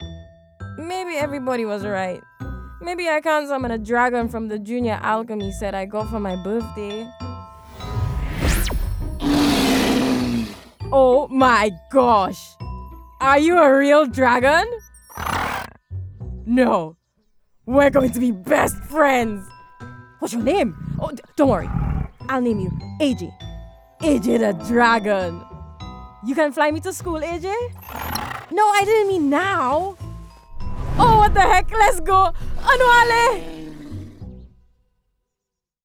Accent: Spanish Characteristics: Confident and Edgy Age: 20-30 Age: 30-40 View on spotlight Spanish Commercial Caribbean Animation
Kids-Animation_Caribbean_FULL-PRODUCTION.wav